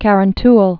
(kărən-təl)